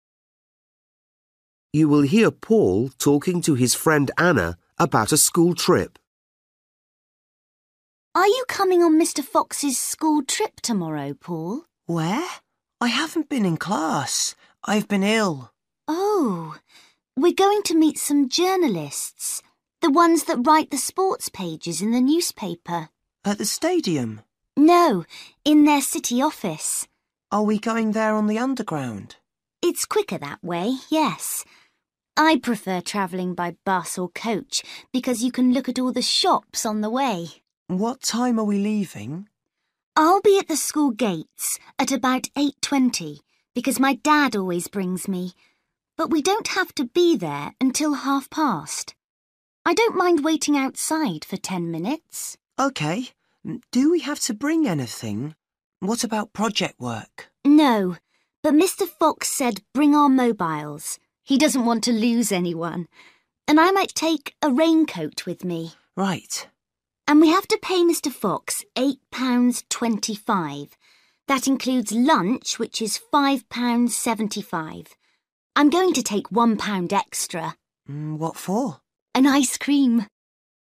Bài tập trắc nghiệm luyện nghe tiếng Anh trình độ sơ trung cấp – Nghe một cuộc trò chuyện dài phần 41